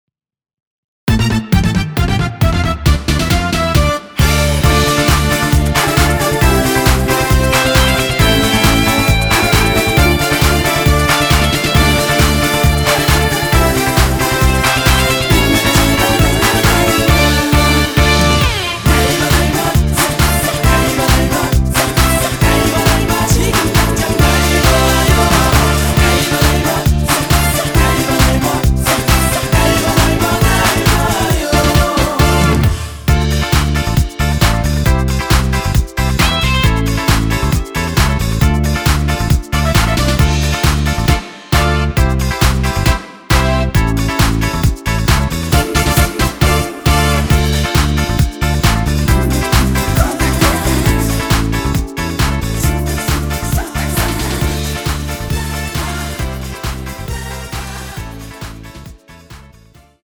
코러스에 귀순 부분은 모두 삭제 하여 원하시는 이름을 넣어서 부르실수 있도록 하였습니다.
원키 코러스 포함된 MR입니다.(미리듣기 확인)
Dm
앞부분30초, 뒷부분30초씩 편집해서 올려 드리고 있습니다.
중간에 음이 끈어지고 다시 나오는 이유는